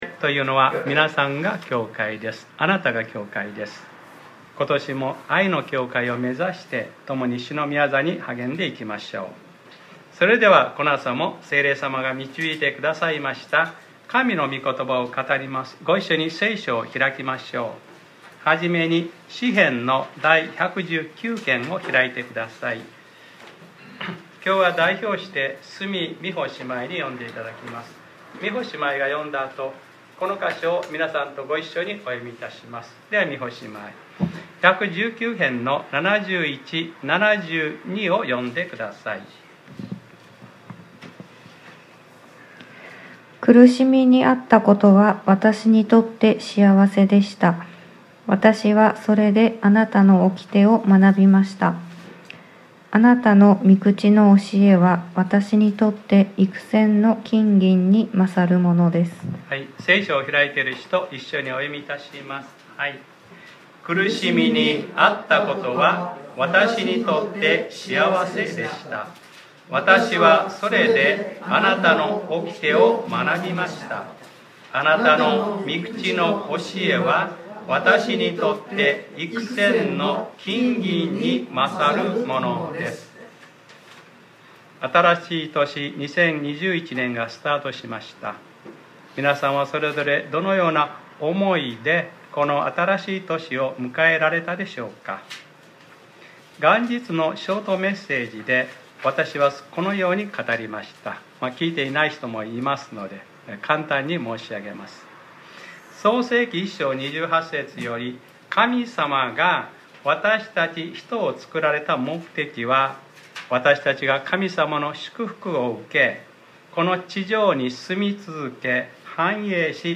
2021年 1月 3日（日）礼拝説教『聖書の価値観で歩みなさい』